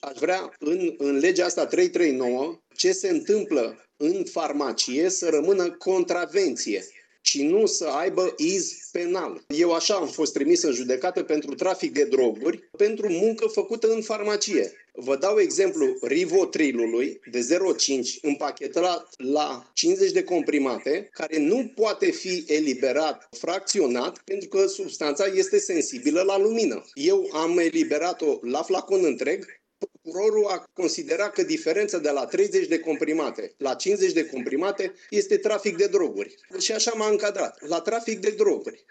farmacist independent